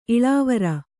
♪ iḷāvara